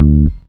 IAR BASS 1.wav